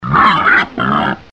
Index of /userimages/file/Voice/animals